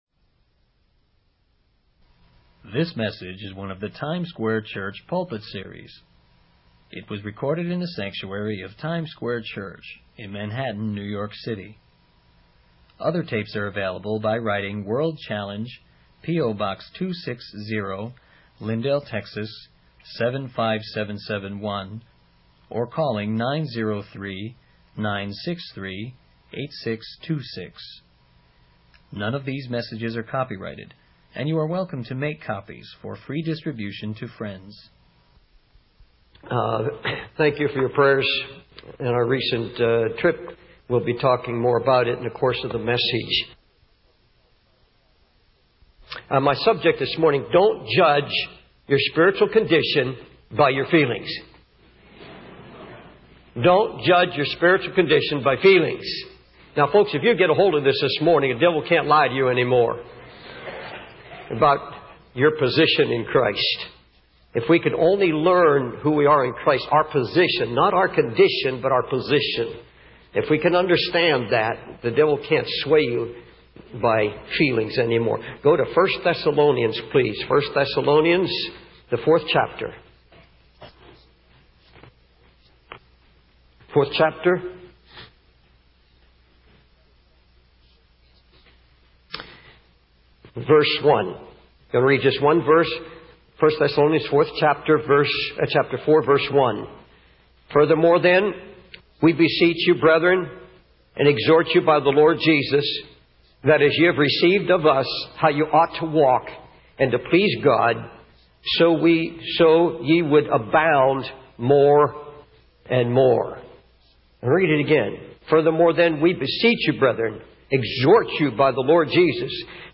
In this sermon, the preacher focuses on 1 Thessalonians 4:1, urging the brethren to walk in a way that pleases God and to continue growing in their faith. The preacher uses the analogy of a child learning to walk to illustrate the process of spiritual growth. He emphasizes that Christians should be giving more of themselves, their resources, and their time to God.